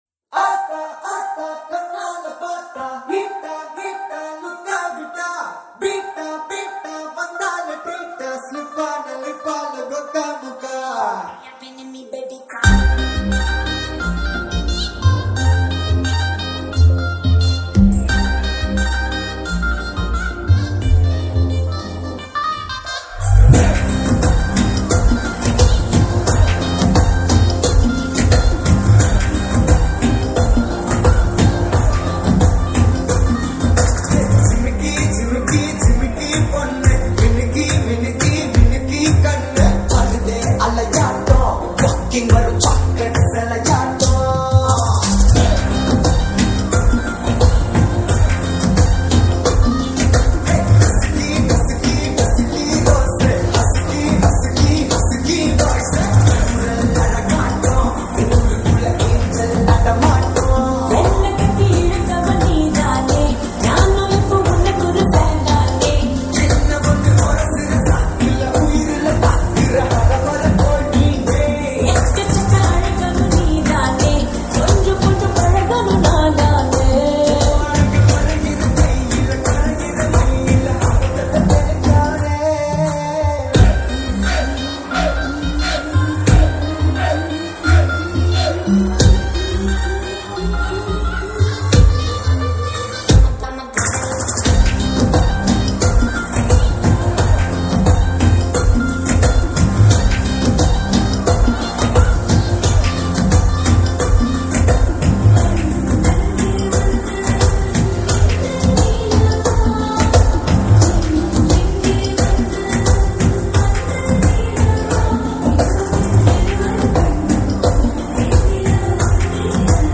ALL TAMIL DJ REMIX » Tamil 8D Songs